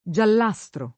[ J all #S tro ]